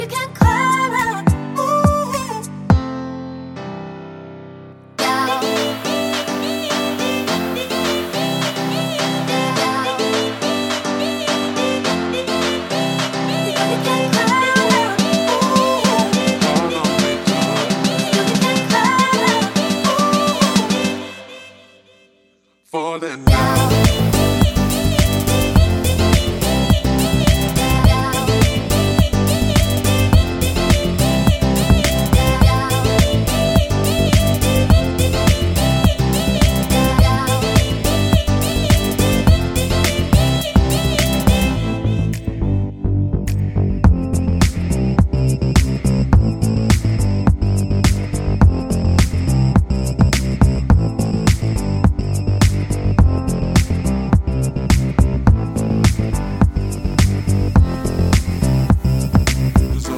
no Backing Vocals Pop (2010s) 3:16 Buy £1.50